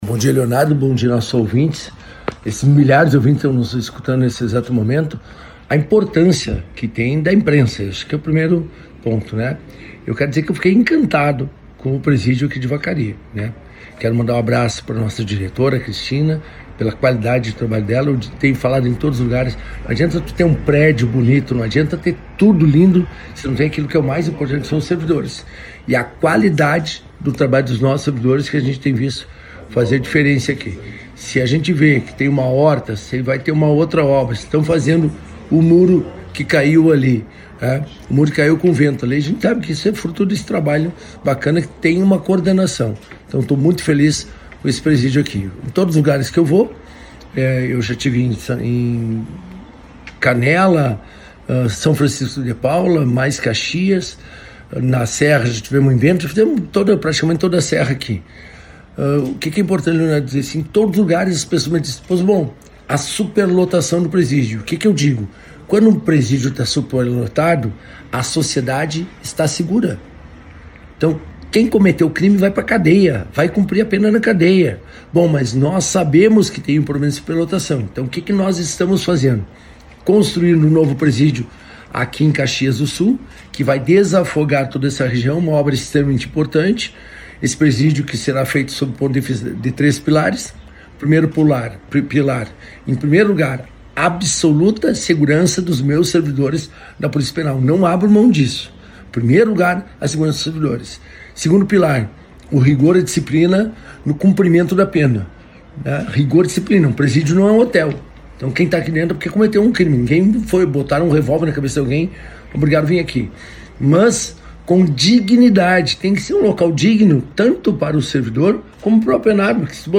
Em entrevista à Rádio Esmeralda, o secretário reforçou a necessidade de retirar o presídio do centro da cidade e disse que essa é uma situação que precisa ser planejada e não acontecerá em curto espaço de tempo, mas que está no radar de ações do Governo estadual.